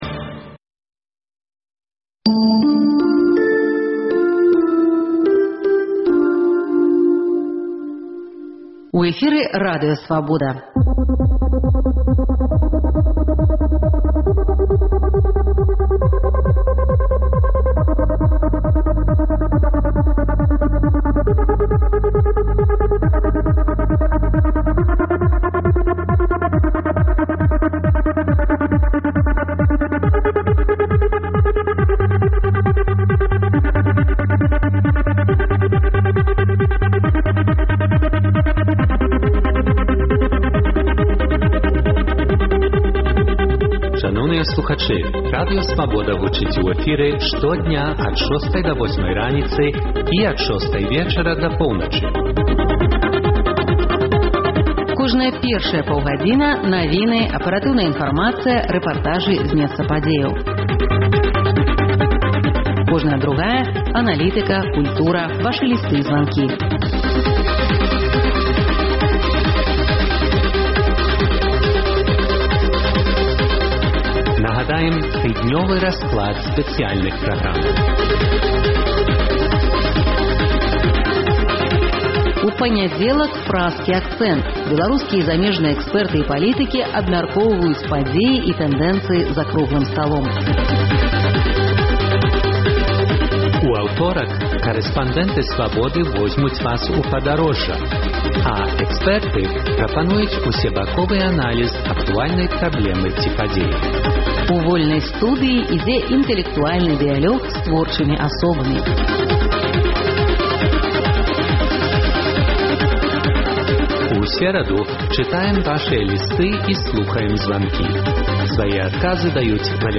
Слухайце ад 12:00 да 14:00 жывы эфір "Свабоды"!